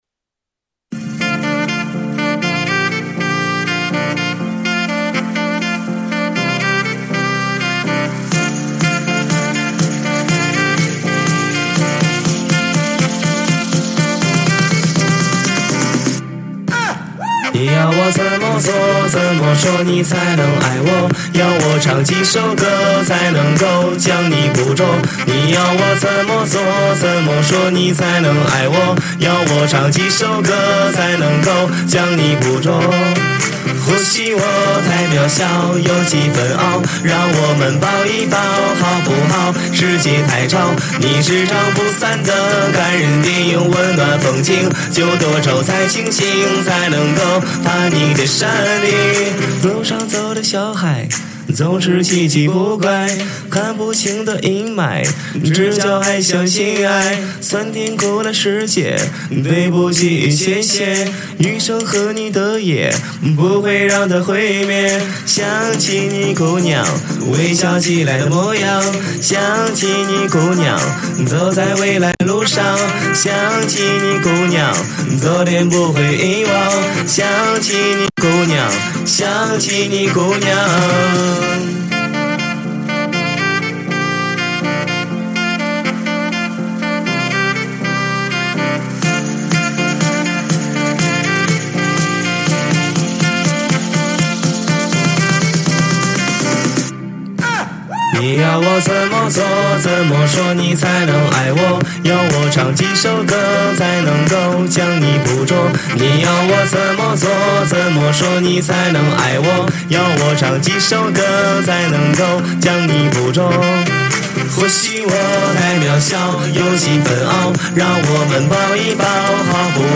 和音
萨克斯